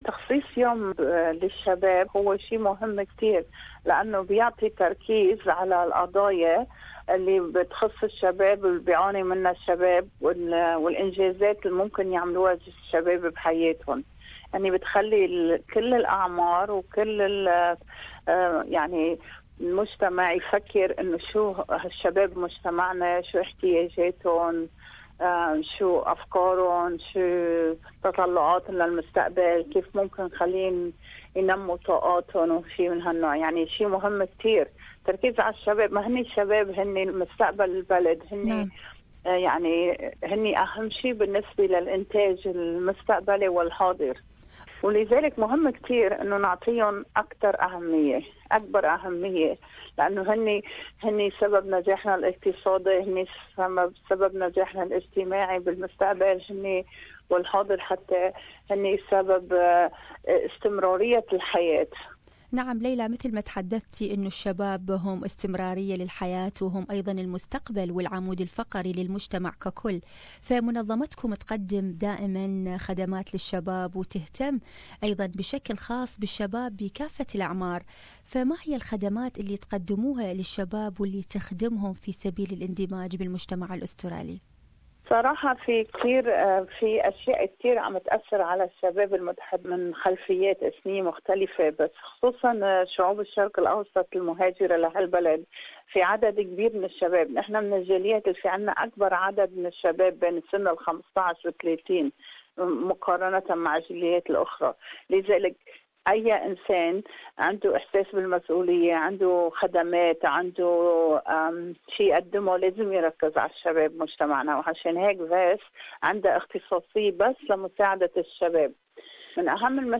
Established in 1988, Global Youth Service Day (GYSD) celebrates and mobilizes the millions of young people who improve their communities through service. More about this issue, listen to this interview